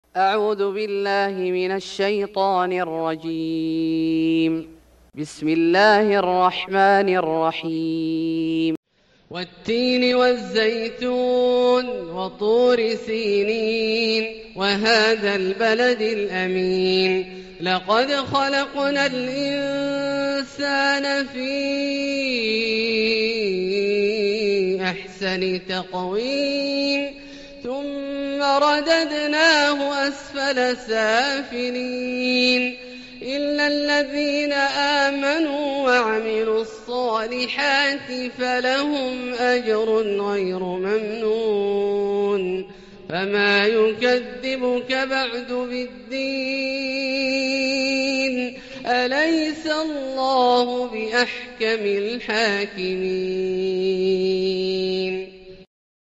سورة التين Surat At-Tin > مصحف الشيخ عبدالله الجهني من الحرم المكي > المصحف - تلاوات الحرمين